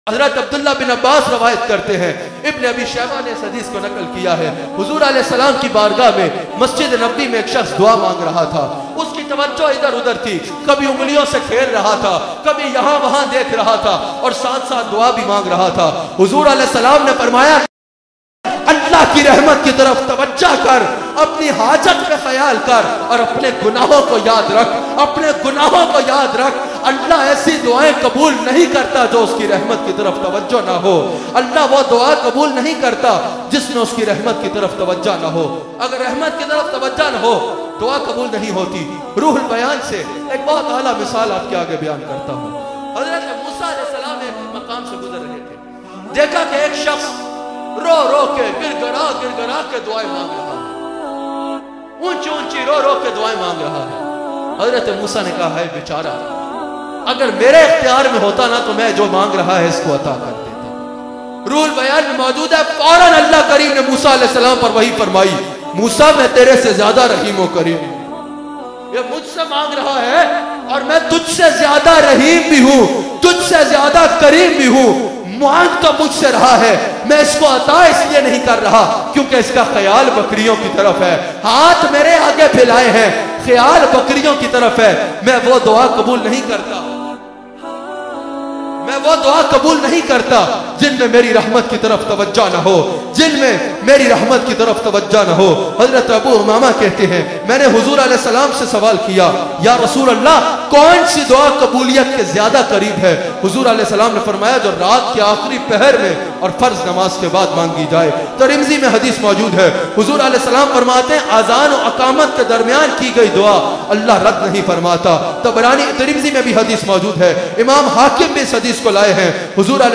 Allah wo duain qabool nai karta bayan mp3